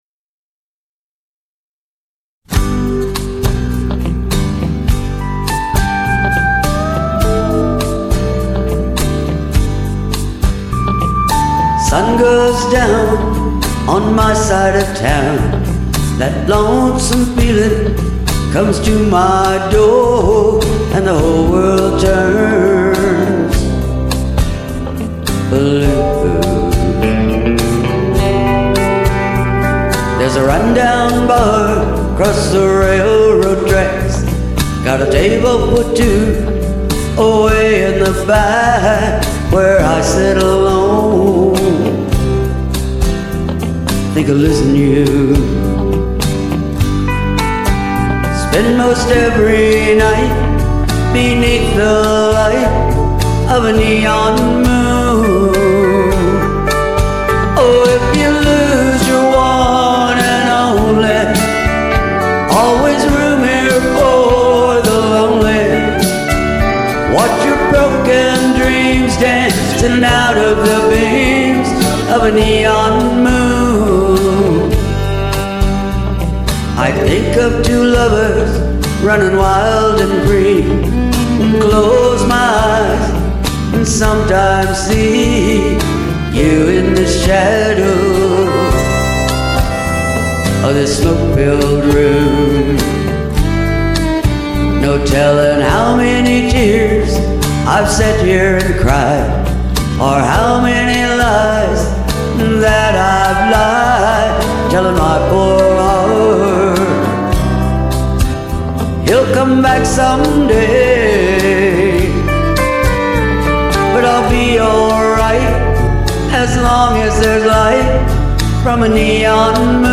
Classic Country, Old Rock N' Roll, Country Gospel, and Blues